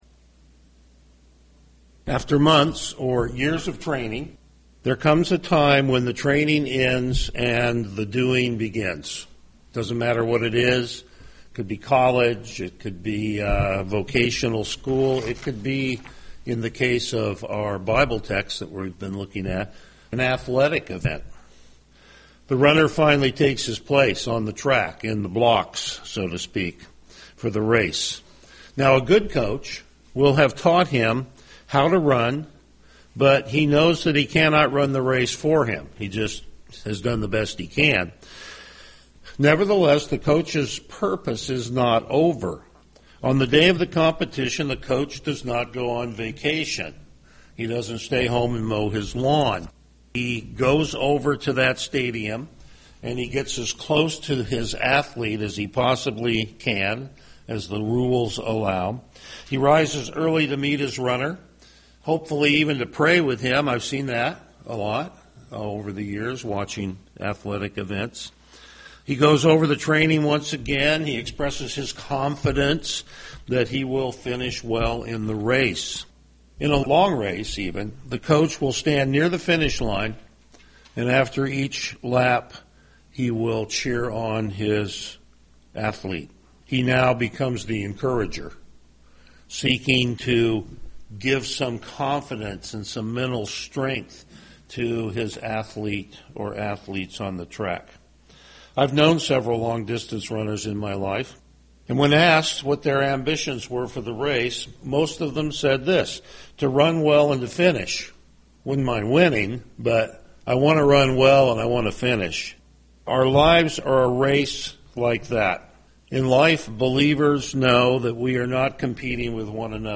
Passage: Hebrews 12:12-17 Service Type: Morning Worship
Verse By Verse Exposition